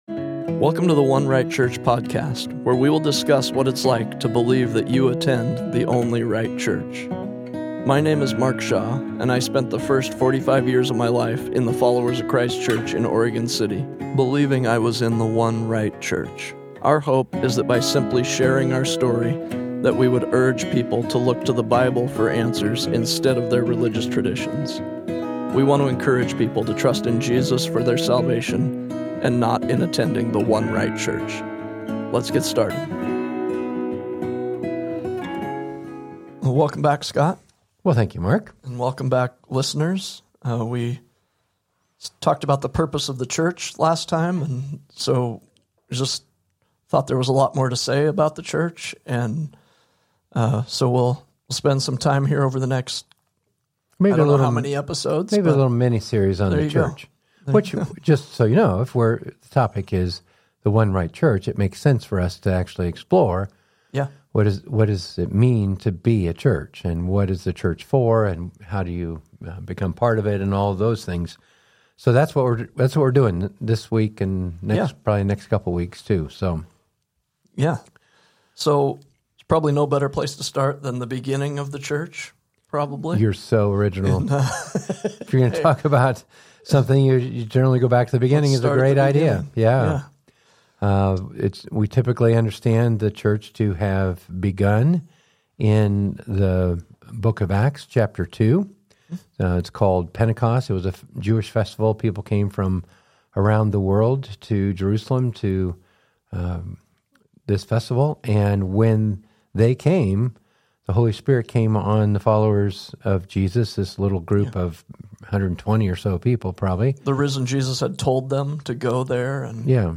This is our discussion of how the church got started.